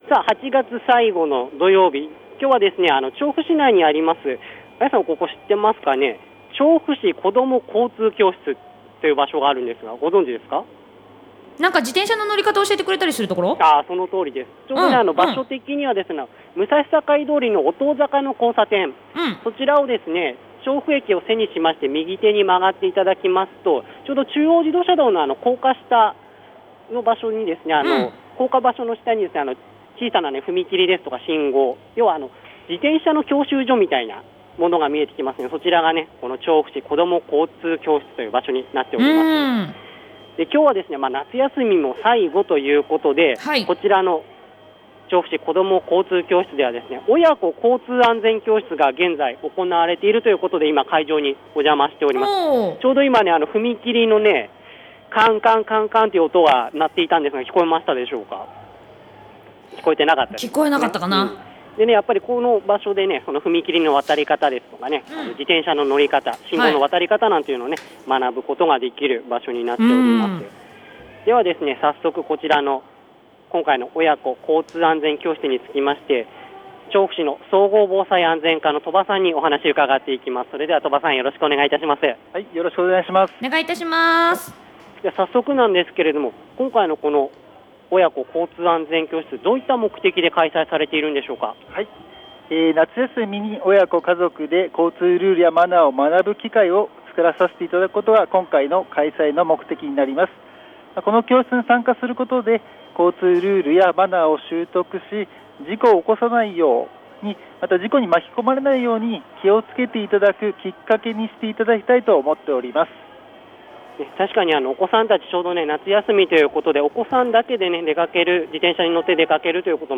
今回は、調布市子ども交通教室で行われていた「夏休み　親子交通安全教室」にお邪魔しました。